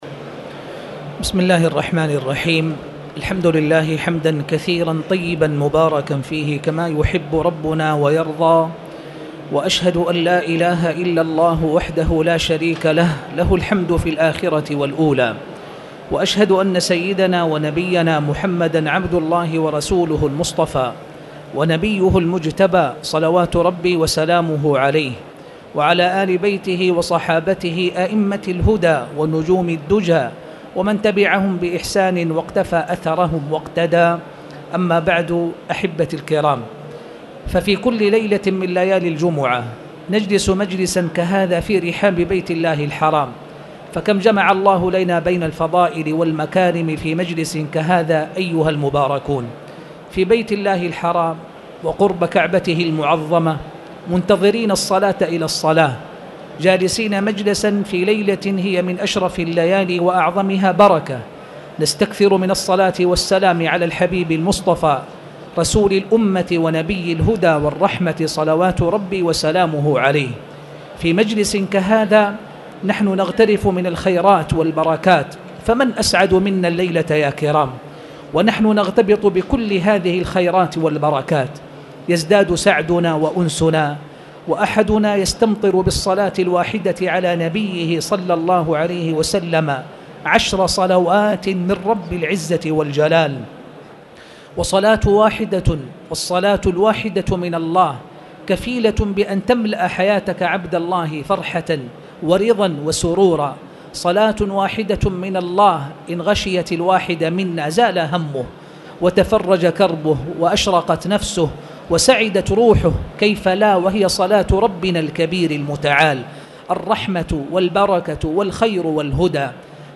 تاريخ النشر ٢٦ جمادى الأولى ١٤٣٨ هـ المكان: المسجد الحرام الشيخ